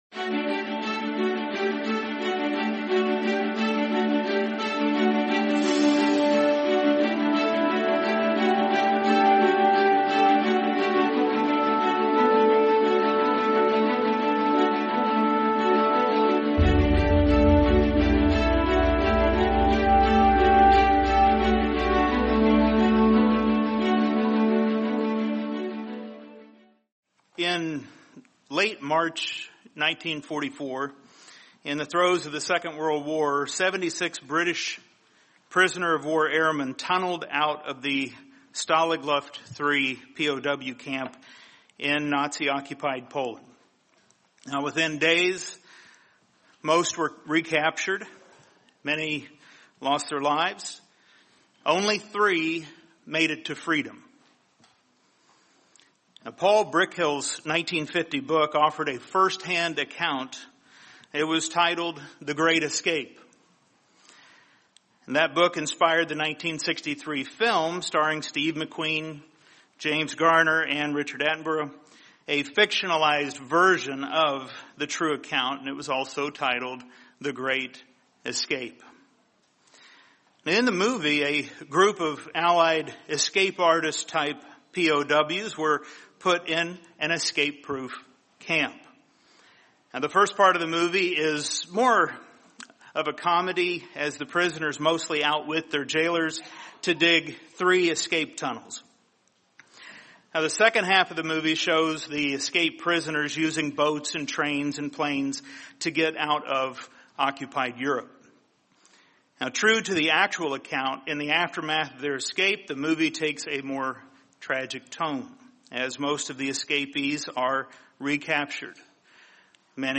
Sermon The Way of Escape